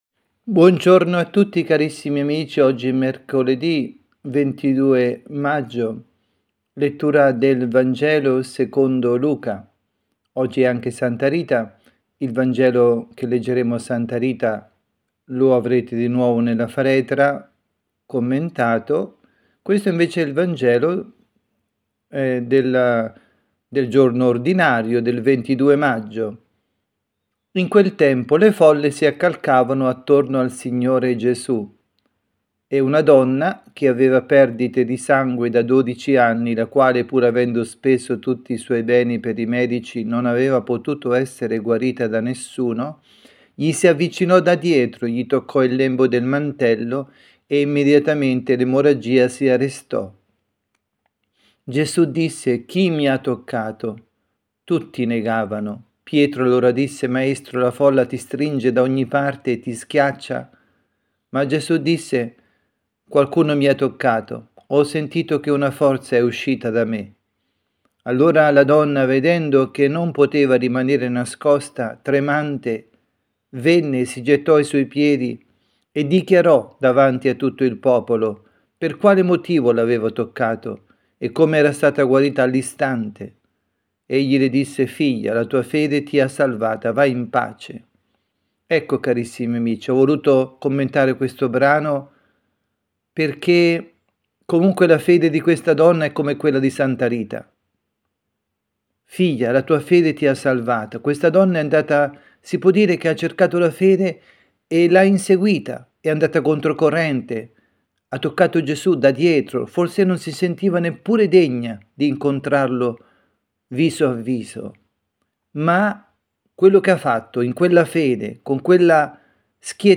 Catechesi
dalla Parrocchia Santa Rita – Milano